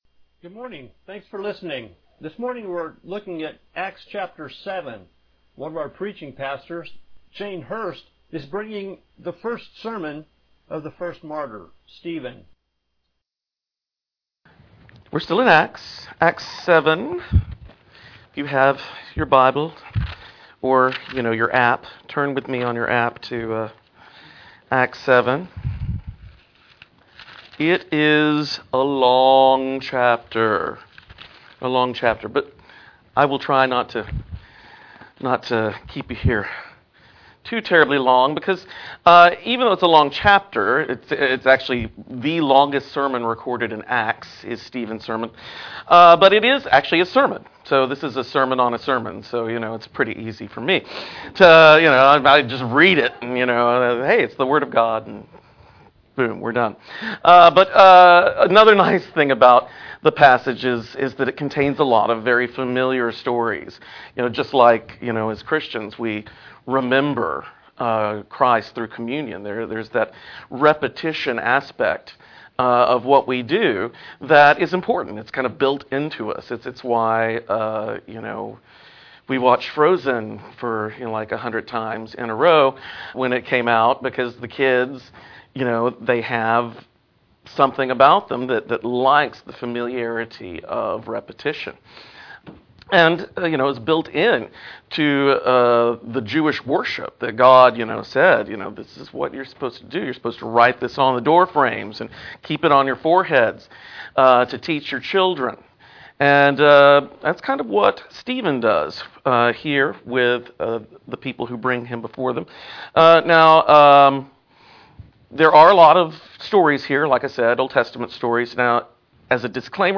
Mar 04, 2018 Acts 7 Stephen’s sermon MP3 SUBSCRIBE on iTunes(Podcast) Notes Discussion Sermons in this Series Stephen the first martyr preaches about Jesus Loading Discusson...